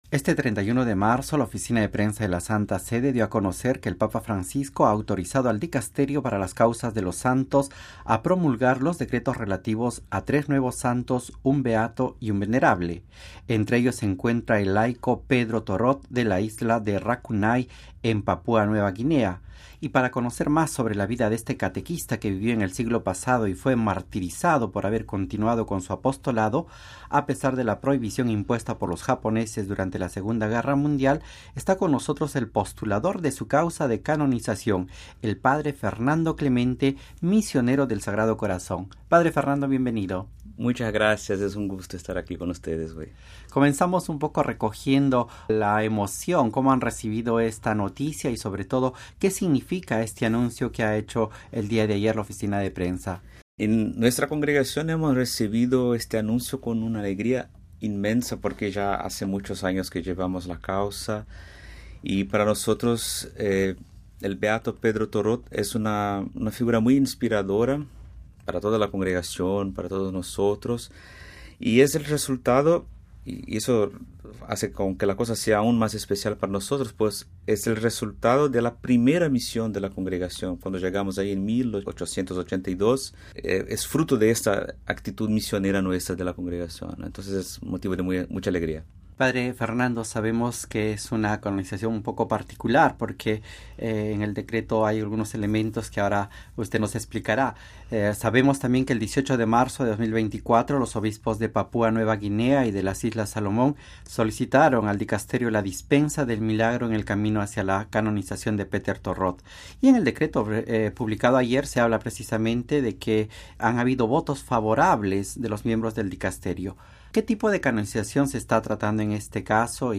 (Tagstotranslate) Saints and Park (T) Pope Francis (T) Missionary (T) Interview (T) Spain (T) Papua New Guinea